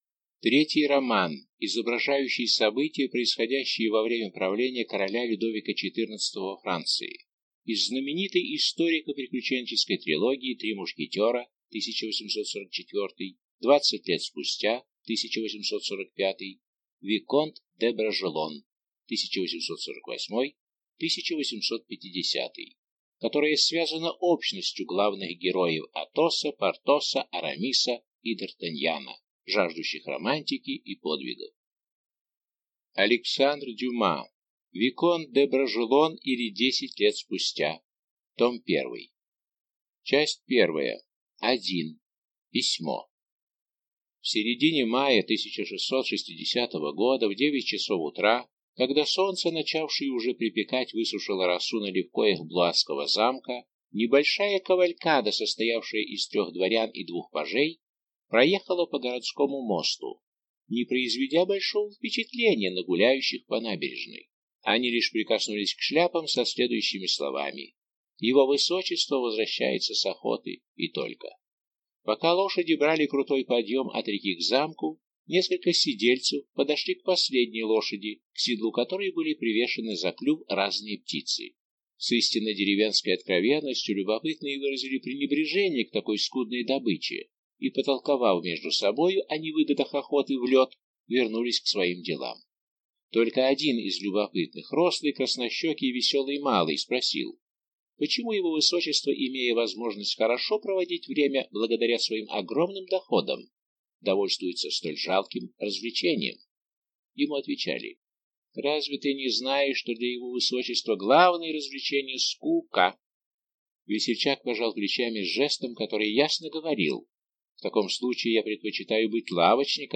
Аудиокнига Виконт де Бражелон, или Десять лет спустя. Том 1 | Библиотека аудиокниг